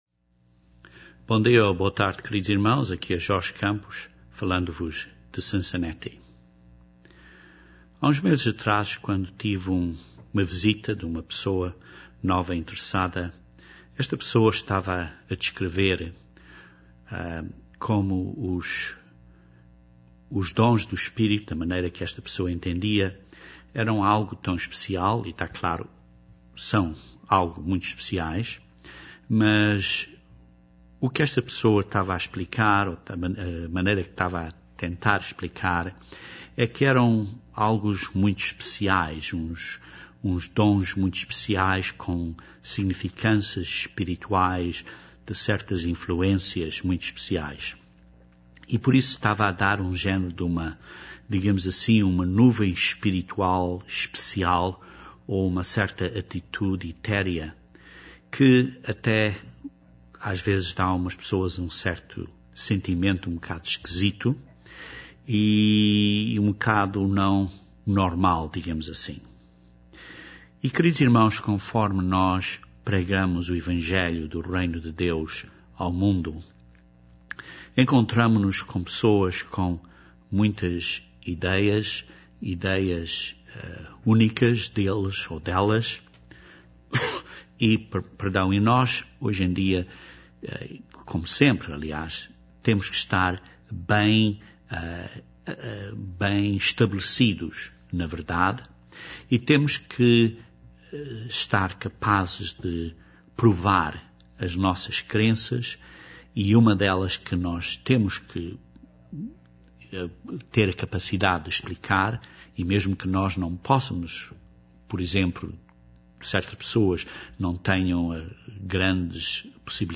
Este sermão analiza várias dávidas de Deus e particularmente os dons do Espírito Santo. Porquê que Deus nos dâ os dons do Espírito Santo?